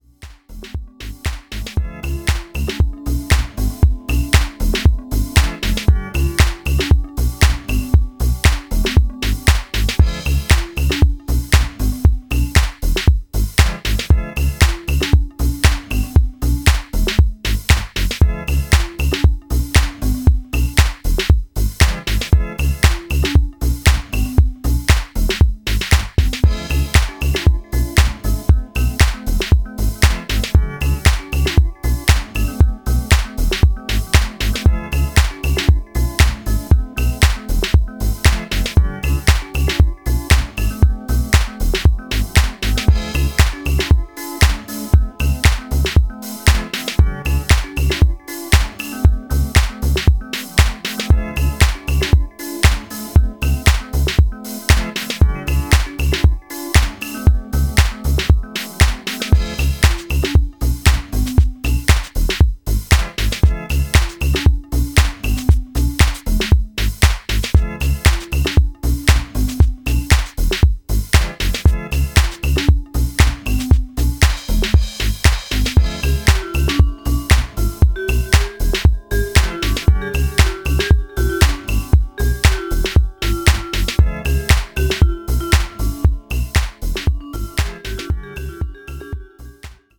ラフでミニマルなディスコサンプルと楽観的で時に調子外れのメロディー、もっさりと足取りを奪うグルーヴ。
何はともあれディスコ/ハウス史に残る、キュートで親しみやすくクレイジーな金字塔です。